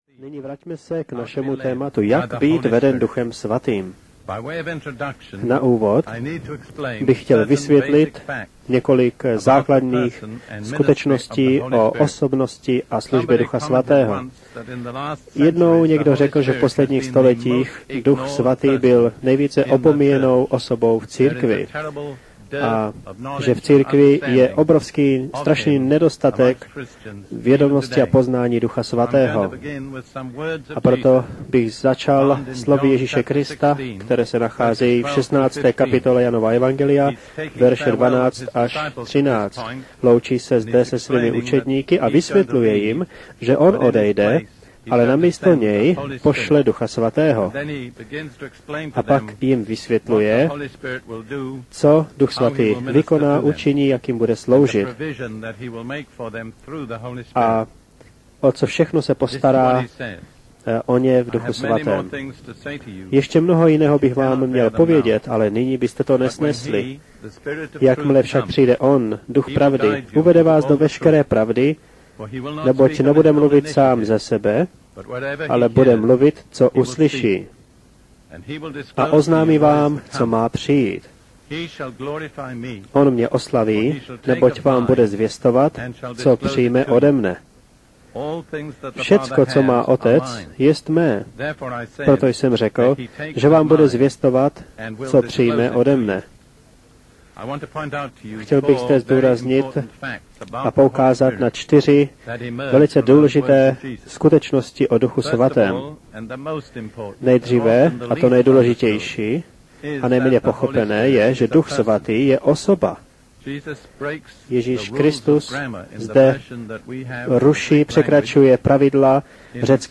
Z rádiového vysílání
s překladem do češtiny – Jak být veden Duchem svatým Vypadá to